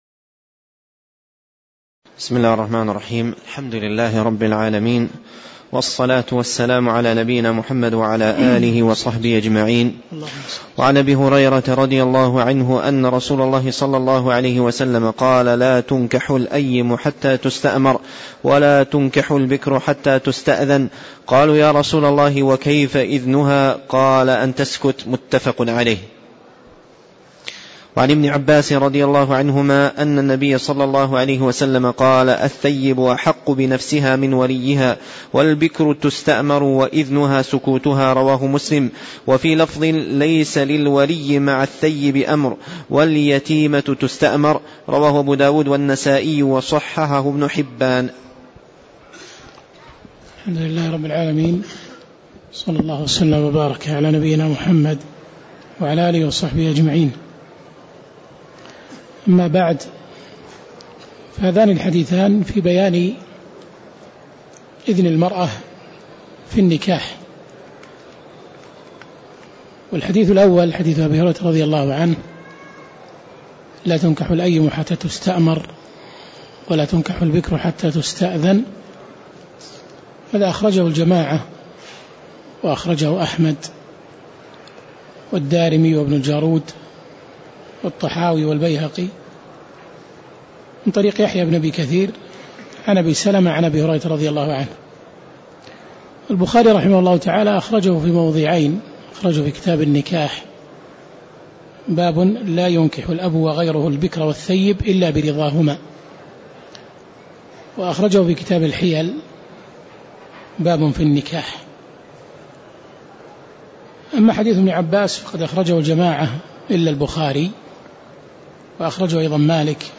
تاريخ النشر ٤ ربيع الأول ١٤٣٧ هـ المكان: المسجد النبوي الشيخ